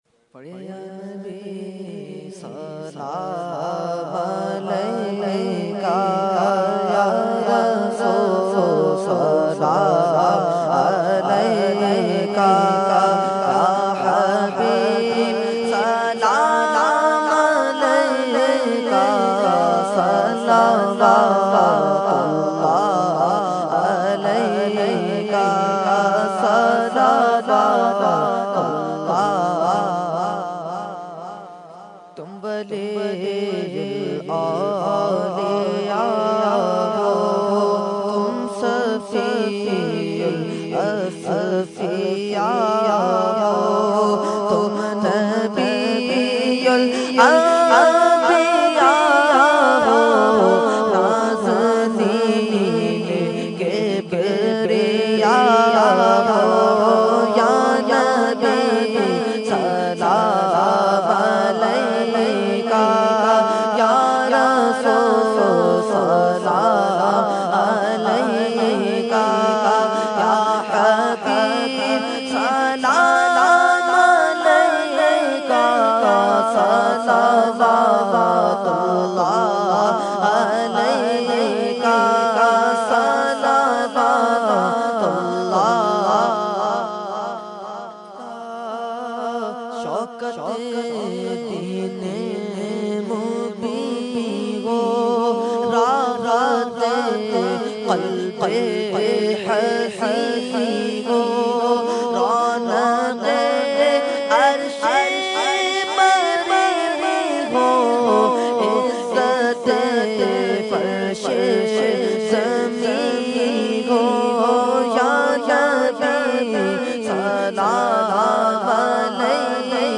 Category : Salam | Language : UrduEvent : 11veen Nazimabad 23 February 2013